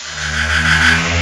WindUp.ogg